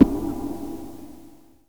RIFFGTR 17-R.wav